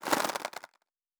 Fantasy Interface Sounds
Weapon UI 06.wav